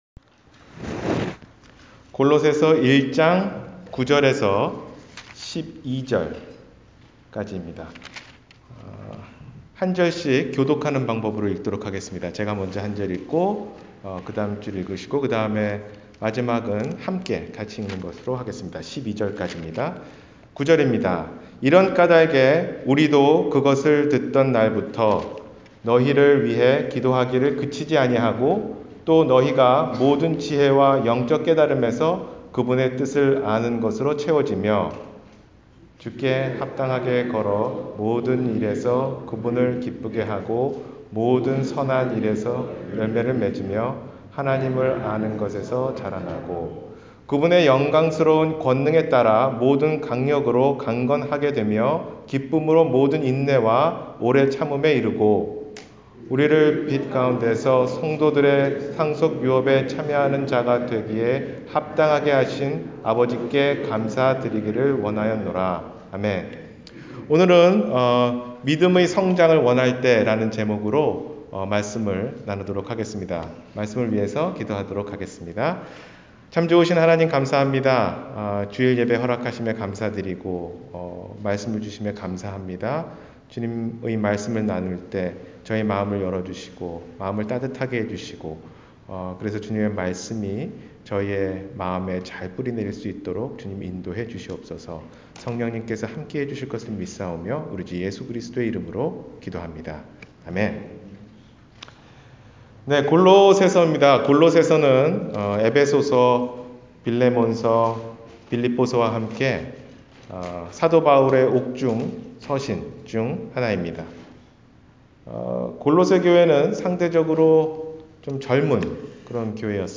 믿음의 성장을 원할 때 – 주일설교